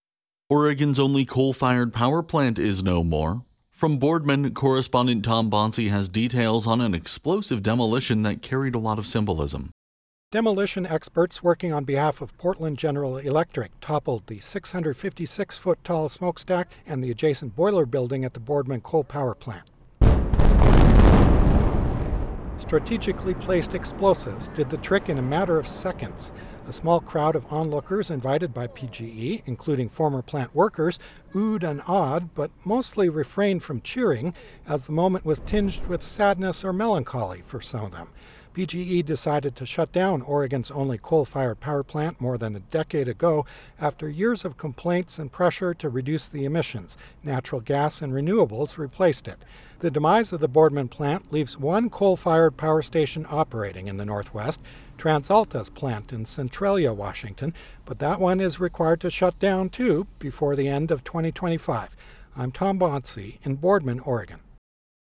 FINAL-Coal-Go-Boom.wav